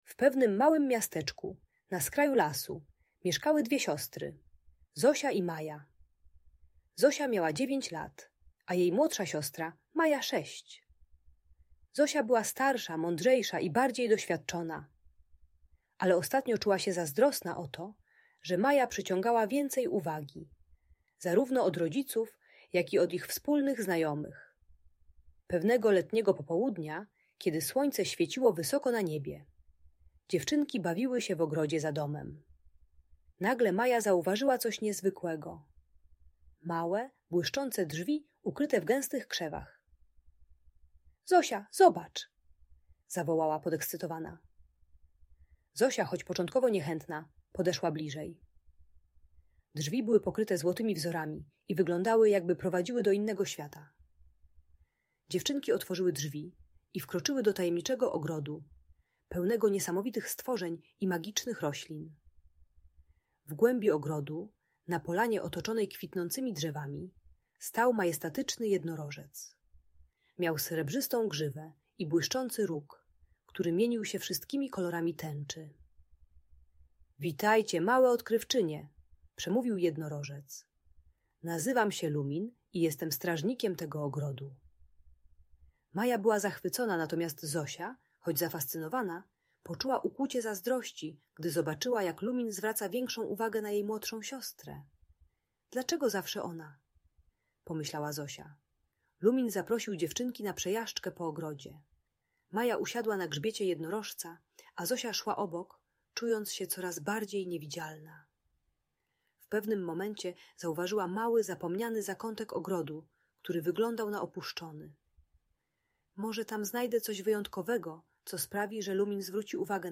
Magiczna historia o siostrzanej miłości i zazdrości - Audiobajka